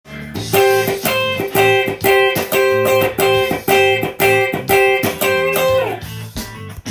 全てAmキーの曲で使えるフレーズになります。
繰り返し弾いています。